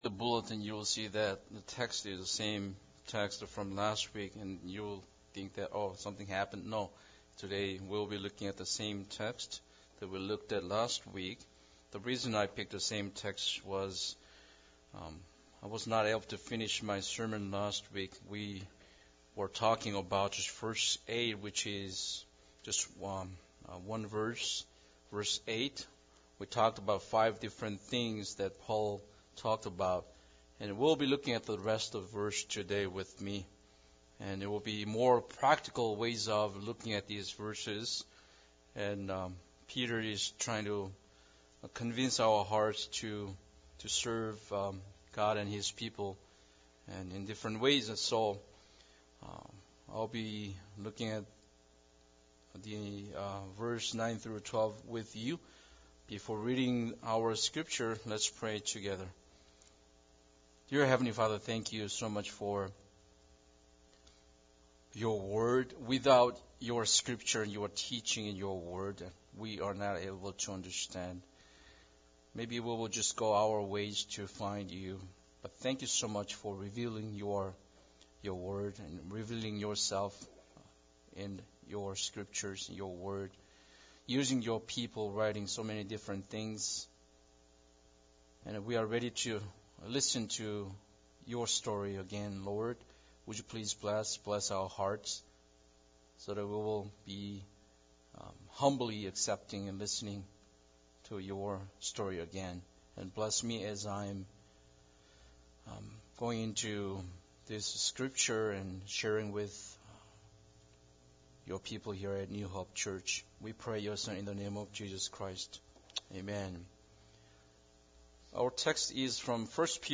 1 Peter 3:8-12 Service Type: Sunday Service Bible Text